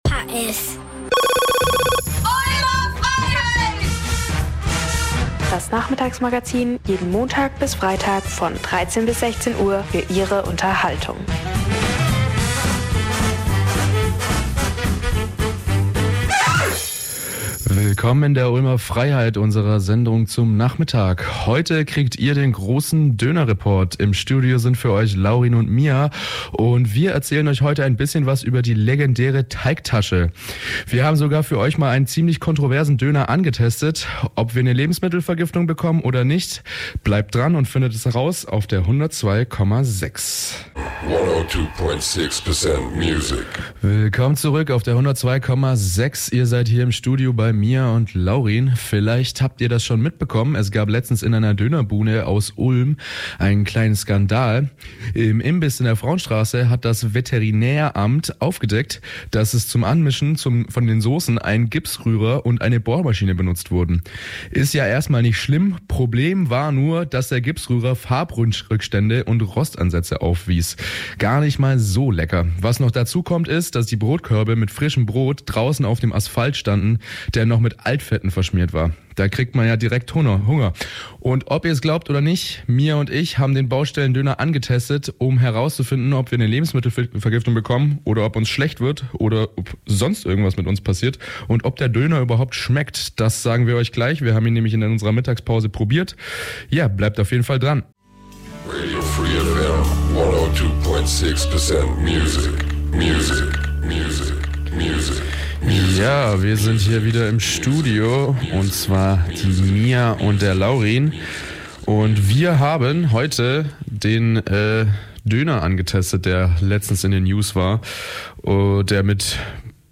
Bei den Ulmern stößt das Quartier jedoch auf gemischte Reaktionen.Wir haben uns vor Ort umgehört und mit den Passanten gesprochen, was ihre Meinung zu den Sedelhöfen ist, was sie bei der Gestaltung anders gemacht hätten und inwiefern die Stadt davon profitiert.